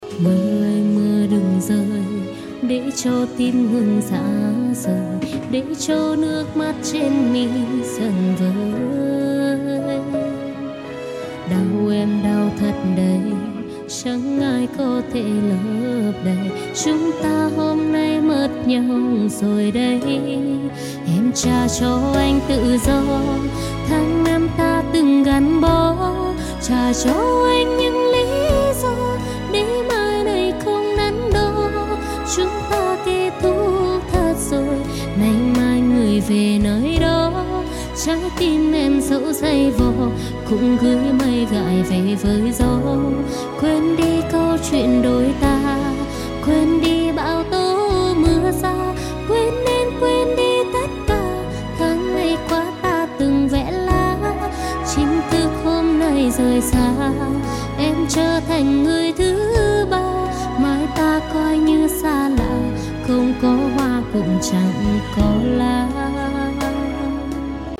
giọng e cảm xúc thật sự.nghe e hát là bị thôi miên ♥♥
Nghe c hát cảm xúc thật